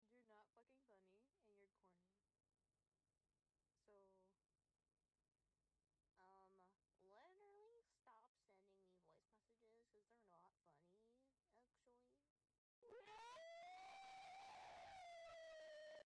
LOUD VOLUME MEME WARNING 🥀 sound effects free download